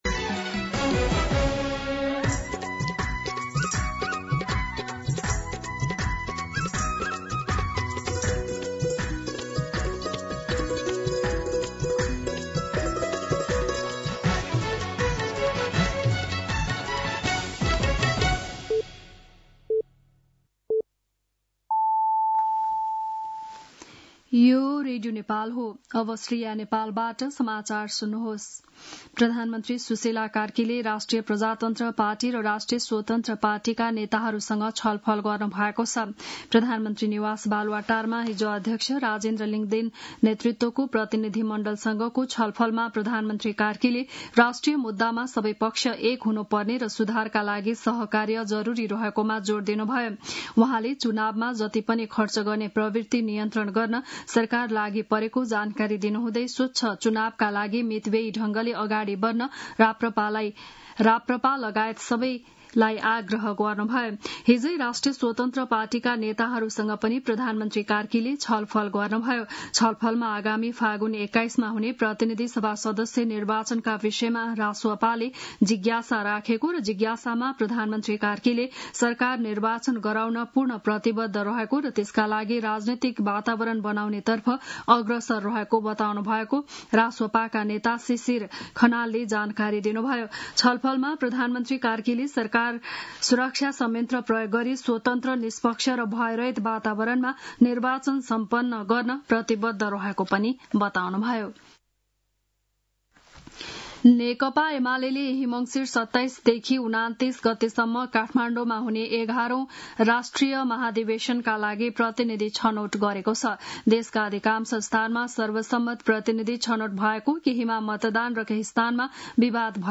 बिहान ११ बजेको नेपाली समाचार : १८ पुष , २०२६